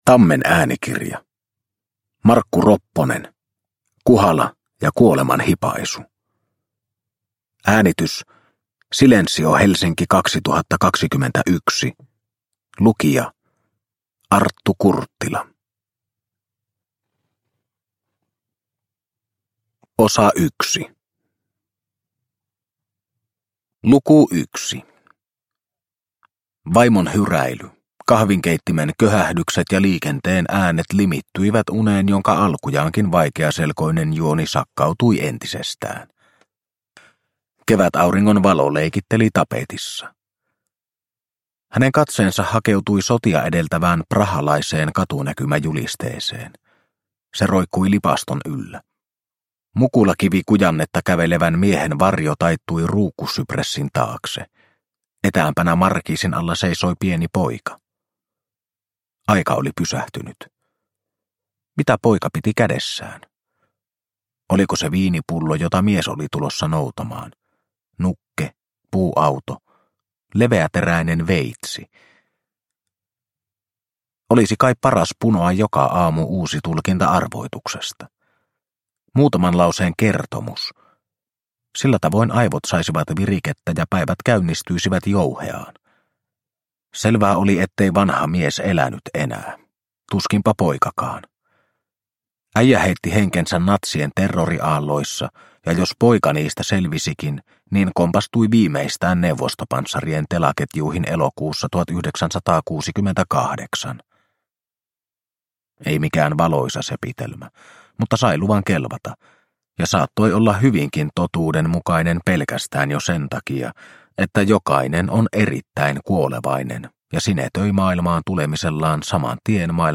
Kuhala ja kuoleman hipaisu – Ljudbok – Laddas ner